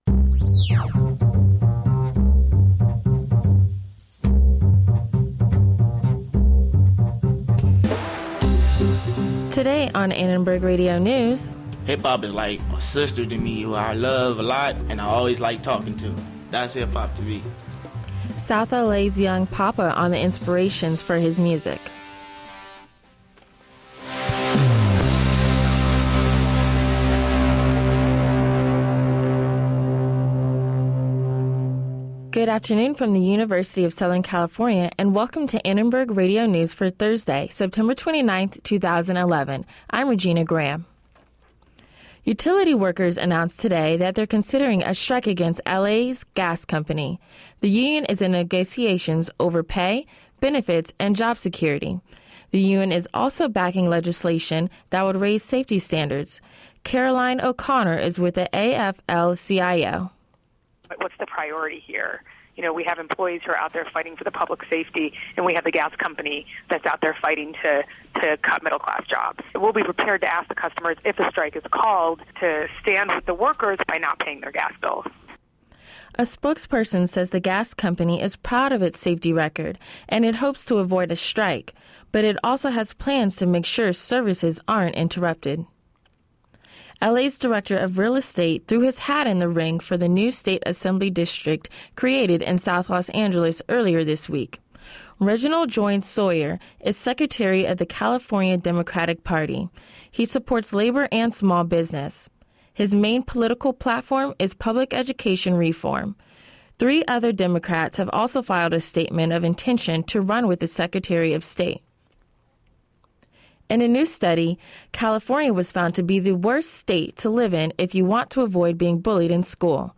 ARN Live Show - September 29, 2011 | USC Annenberg Radio News